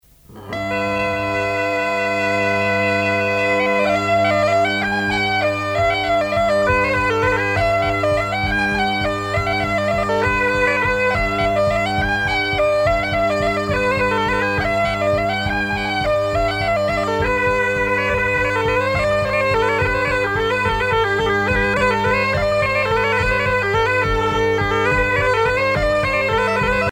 danse : bourree
Pièce musicale éditée